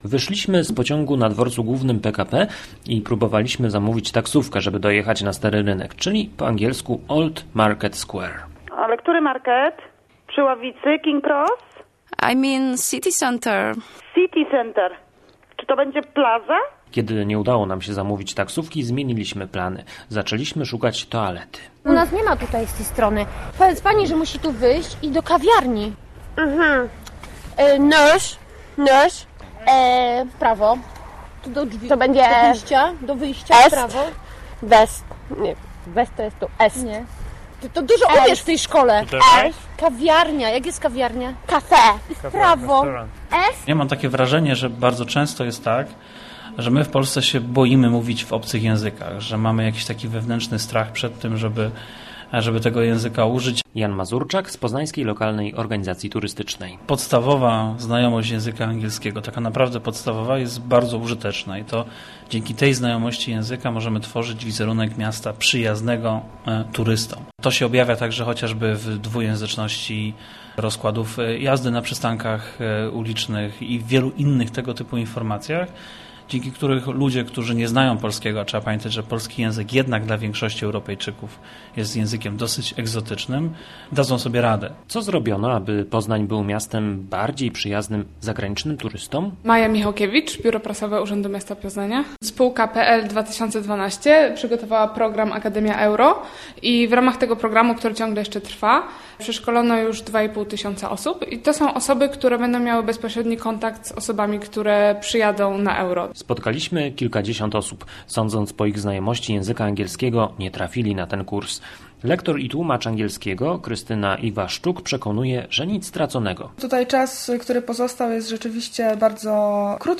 Obcokrajowcy, którzy przyjadą na mistrzostwa Europy do Poznania będą mieli ogromne problemy z... zamówieniem taksówki. Udowodnili to nasi reporterzy, którzy wcielili się w anglojęzycznych turystów.